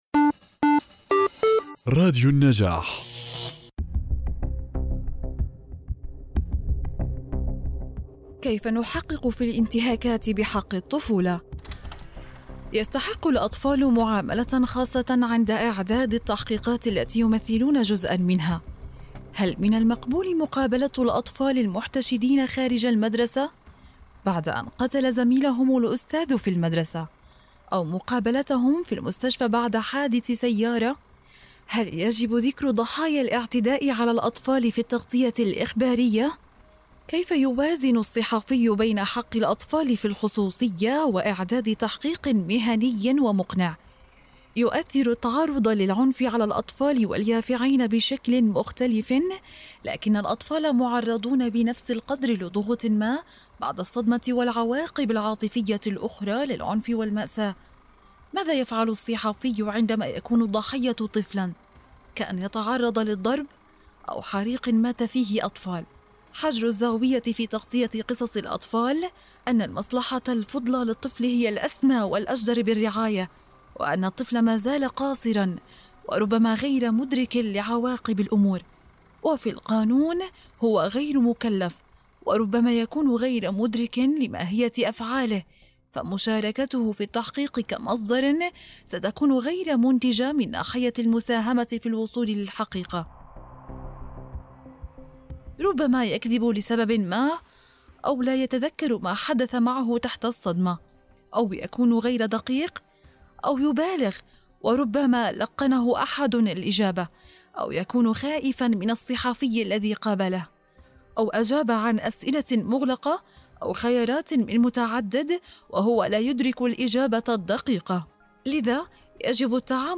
الكتاب المسموع
تتميز القراءة بتوصيل متقن ومشوق